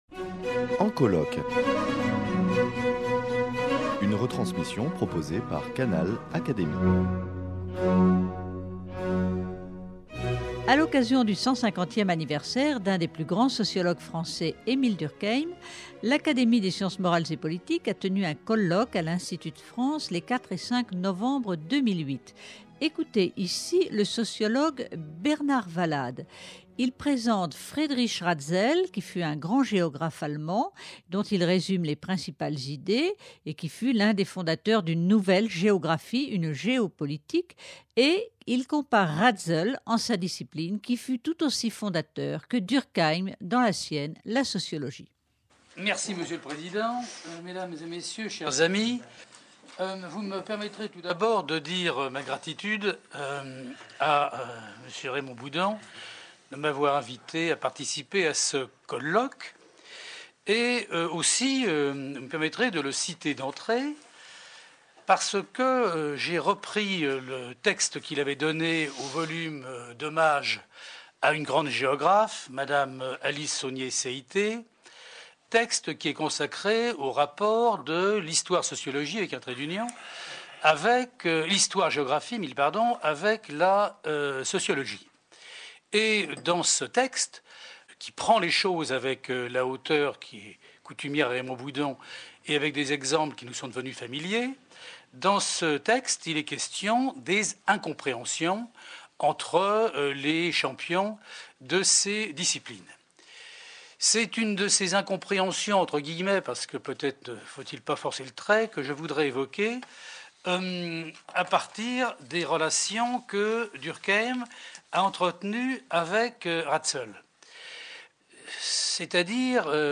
lors d’un colloque pour célébrer Durkheim organisé en novembre 2008 par l’Académie des sciences morales et politiques. Ratzel fut l’un des fondateurs d’une nouvelle géographie, une géo-politique, et Ratzel, en sa discipline, fut tout aussi fondateur que Durkheim dans la sienne, la sociologie.